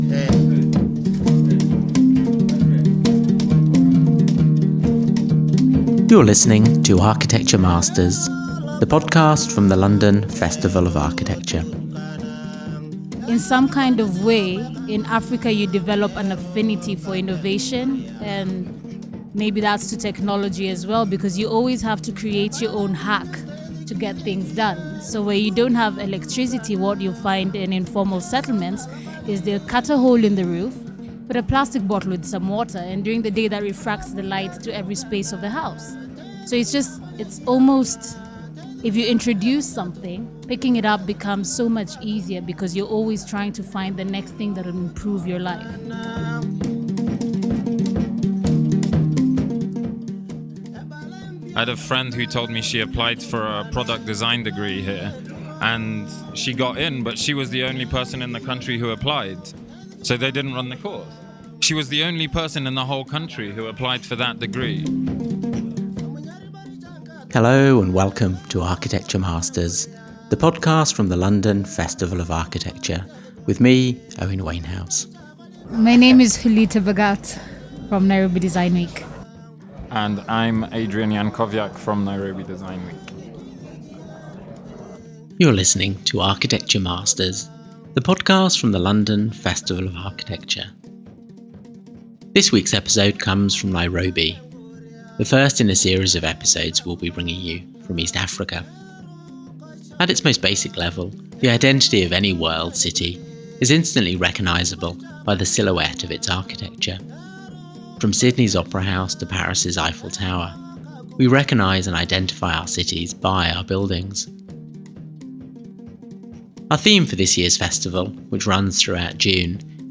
This episode was recorded in Nairobi, the first in a series of episodes we’re…